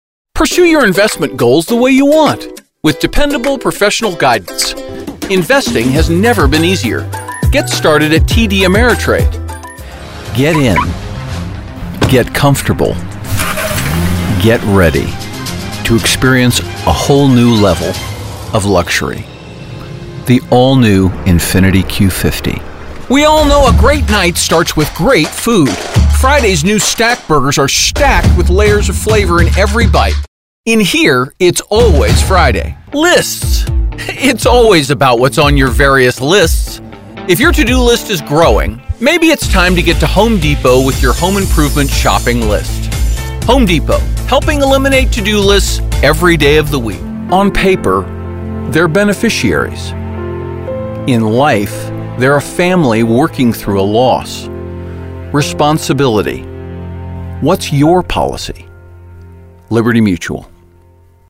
Male
Adult (30-50), Older Sound (50+)
Enthusiastic, articulate, empathetic, lyrical, believable and casual. east coast edges when appropriate along with energy and enthusiasm to match.
Commercials
Words that describe my voice are articulate, sincere, narrator.